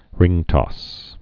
(rĭngtŏs)